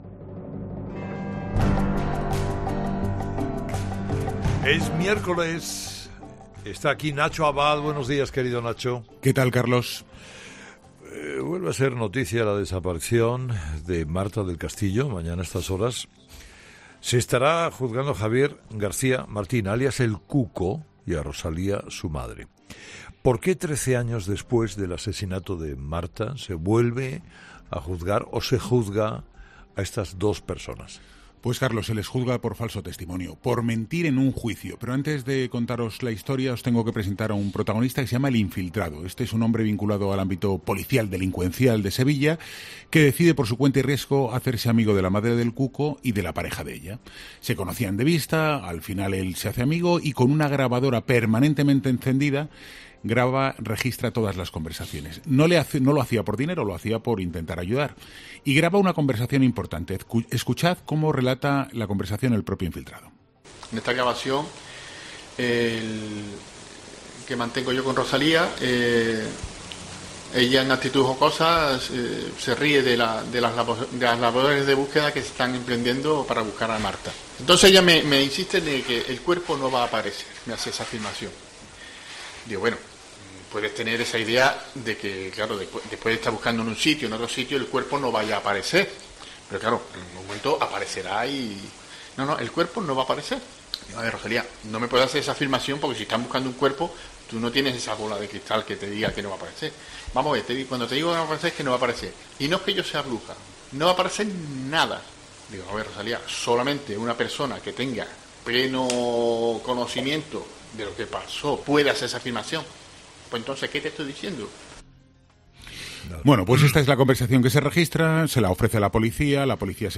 El chiste del Comandante Lara que desata carcajadas en 'Herrera en COPE': "Muy intelectual"